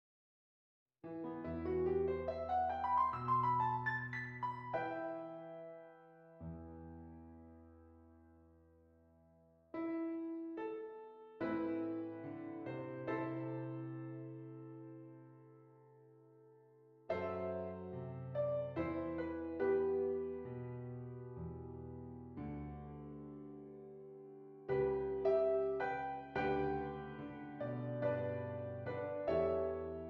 E Minor
Lento